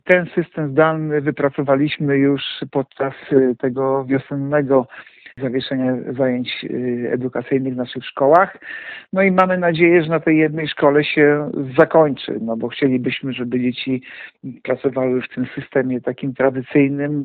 – Mamy wypracowane procedury i jesteśmy na taką sytuację gotowi – mówi Artur Urbański, zastępca prezydenta Ełku odpowiedzialny za edukację.